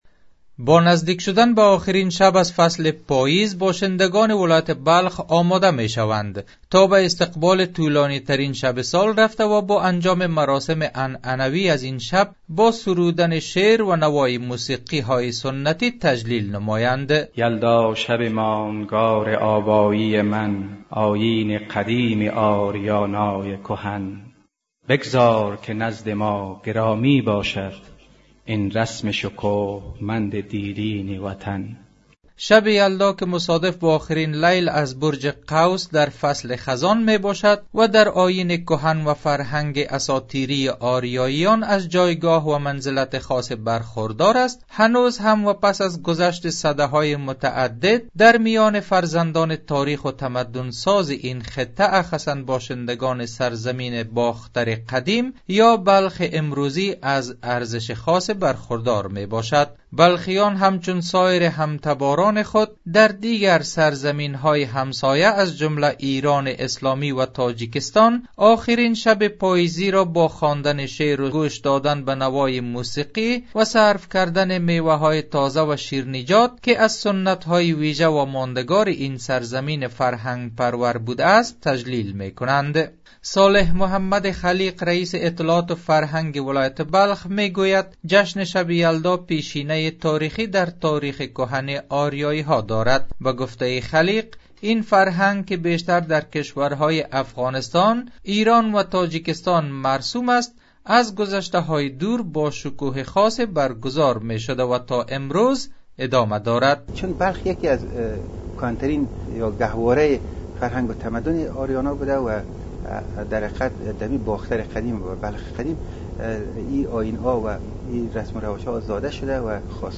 گزارش؛ آمادگی باشندگان ولایت بلخ برای برپایی آیین شب یلدا